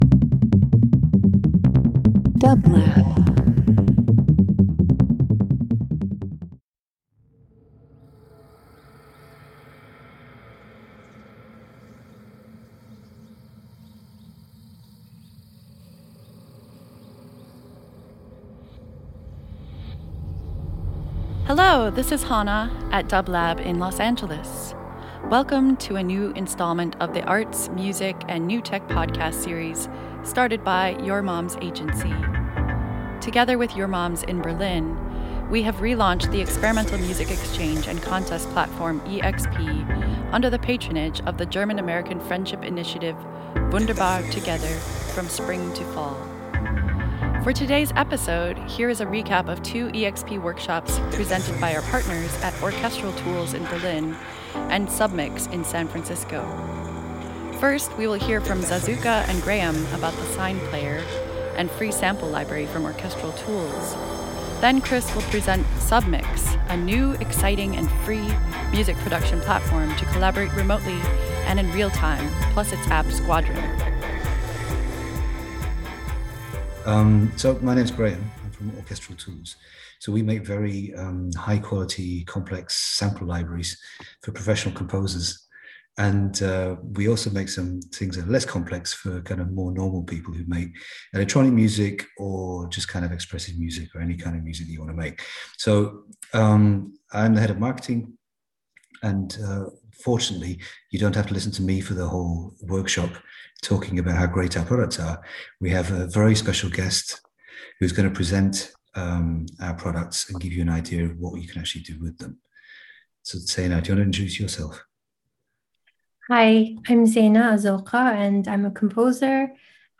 Classical Electronic Experimental International Interview